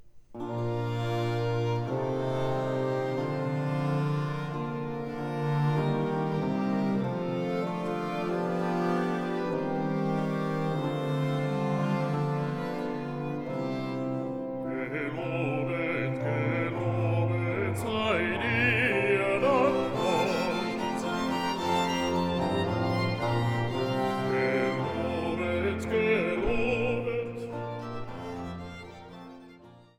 Kantate